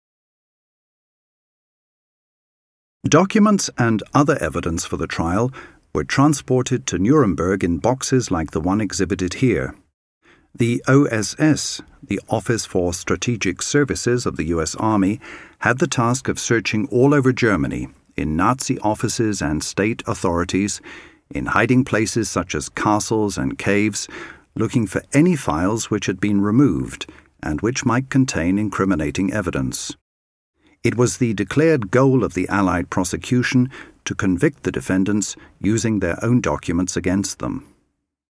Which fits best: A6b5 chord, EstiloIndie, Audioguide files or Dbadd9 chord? Audioguide files